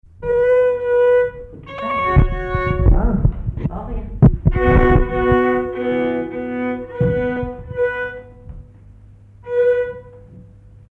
Accordage